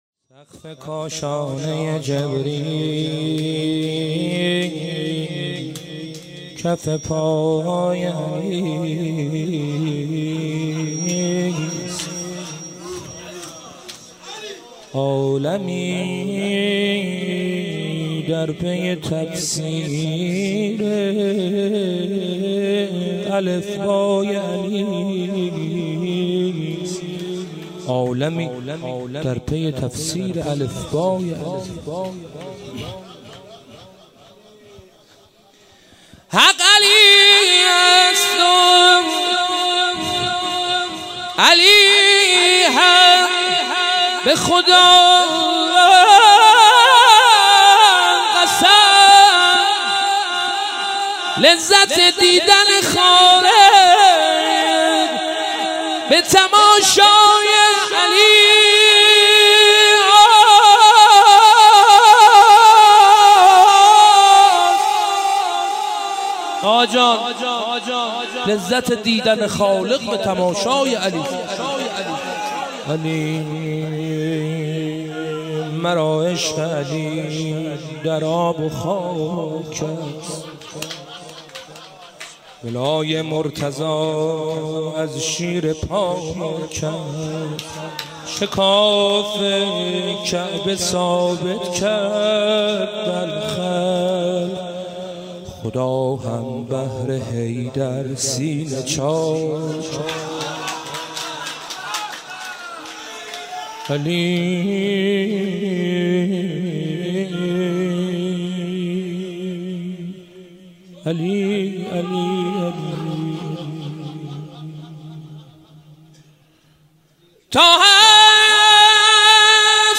سقف کاشانه ی جبریل کف پای علی ست | شعرخوانی | مدح حضرت امیرالمومنین
جلسه ی هفتگی